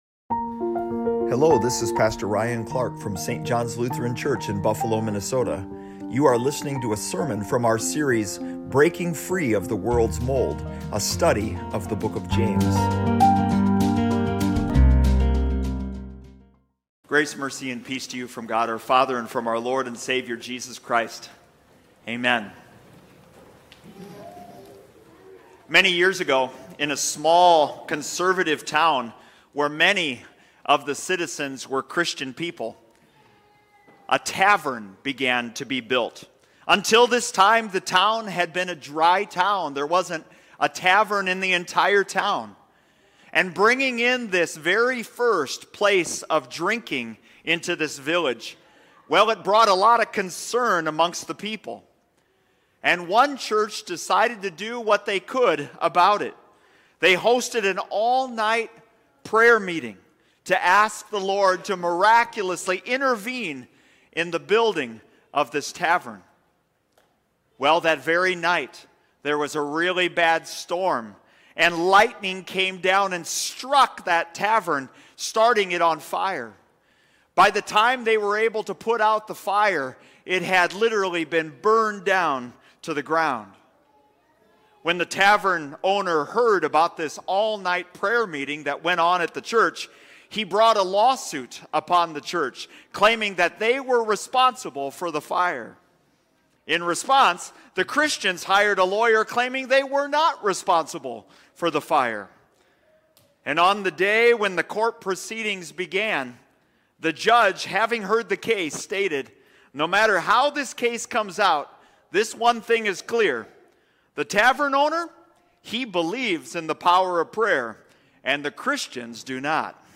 Sermon Home Are you suffering, satisfied, or somewhere in between?